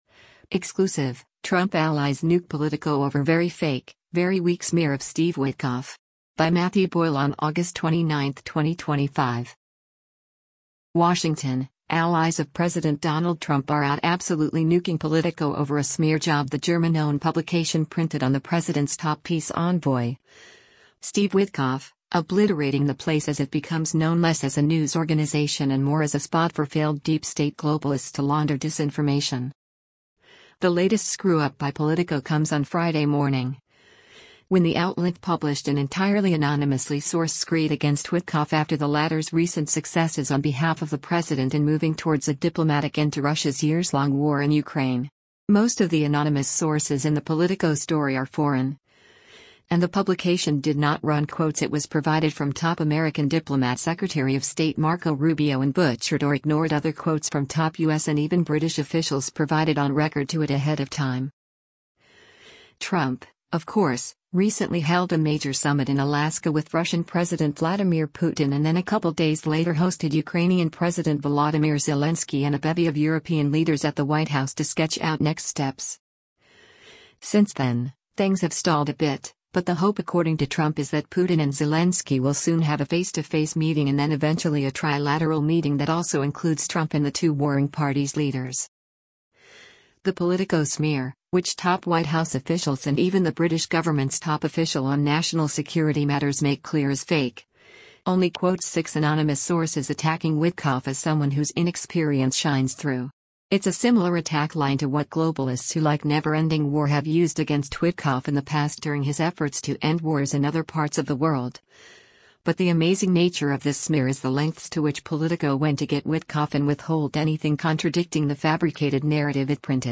Washington, DC